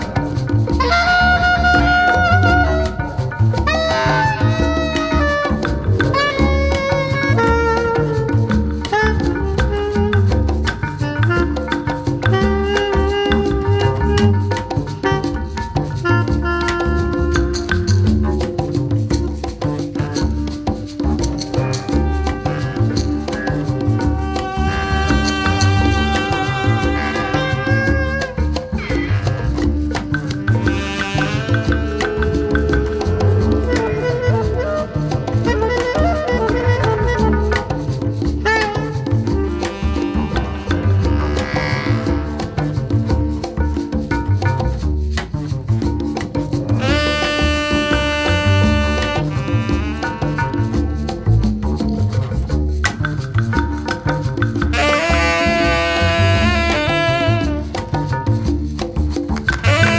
Pharoah Sandersを彷彿とさせる、生々しくタフでありながら優美なサックス。